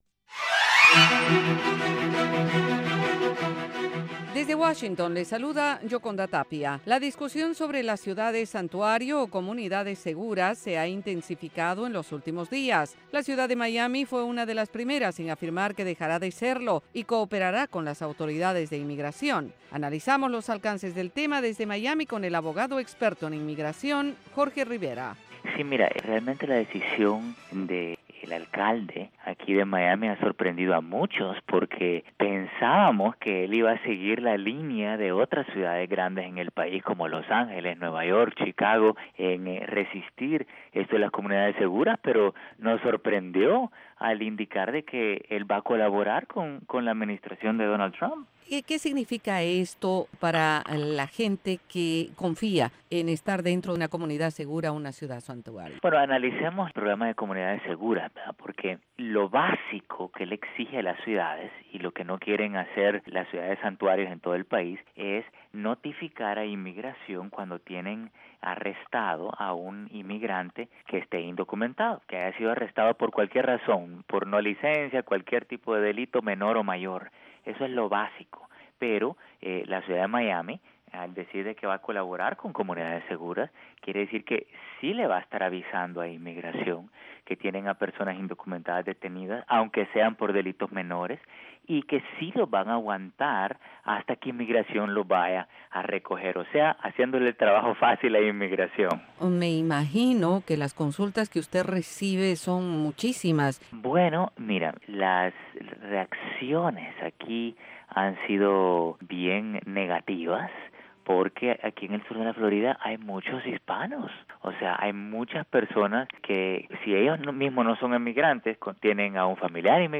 Entrevista VOA - 12:30pm
La Voz de América entrevista, en cinco minutos, a expertos en diversos temas.